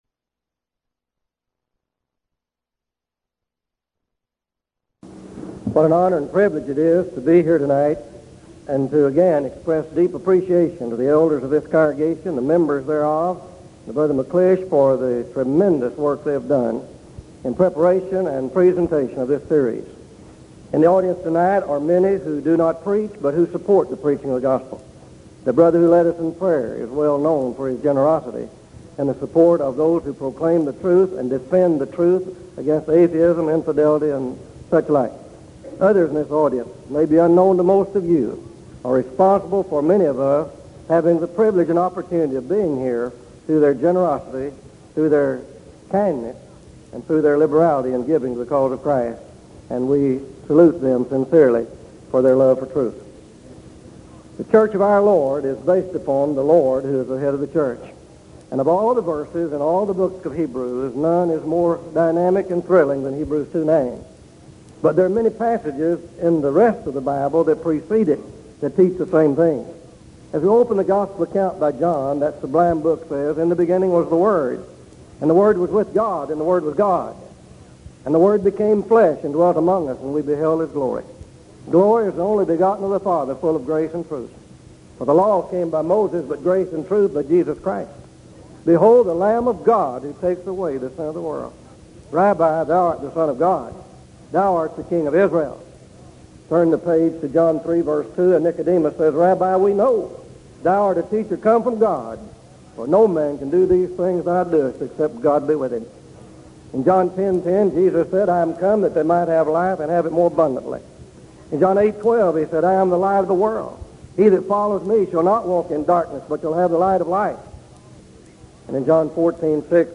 Event: 1983 Denton Lectures Theme/Title: Studies in Hebrews